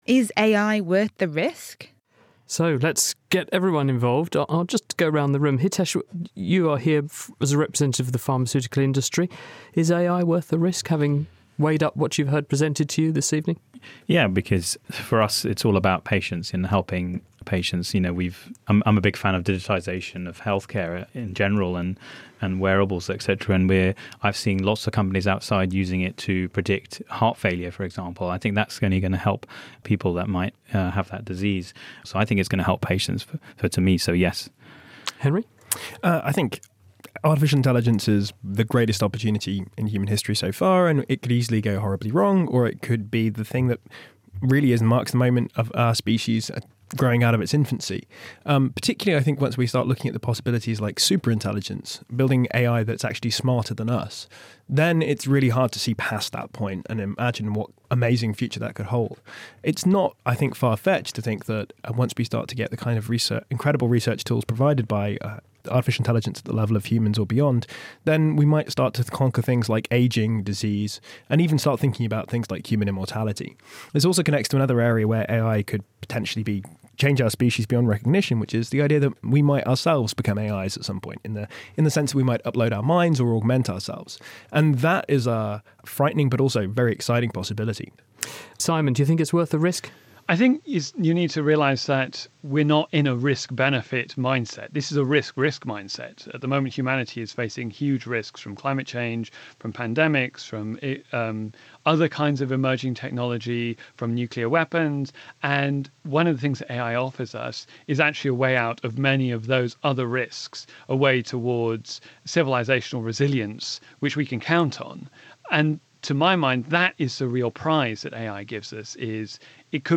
2. Interviews